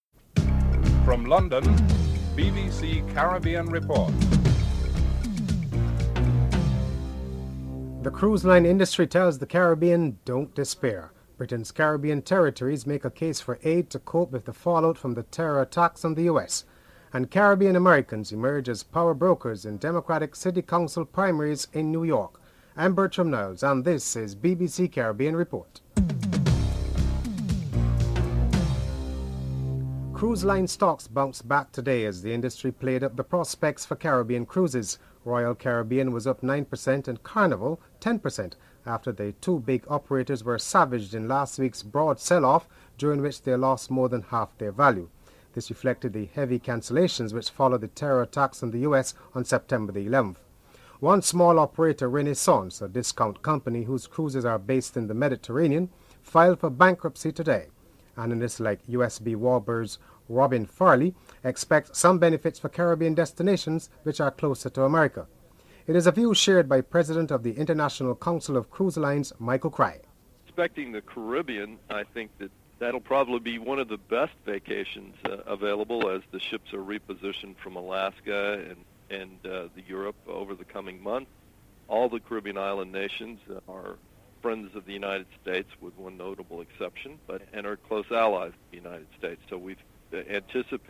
dc.formatStereo 192 bit rate MP3;44,100 Mega bits;16 biten_US
dc.typeRecording, oralen_US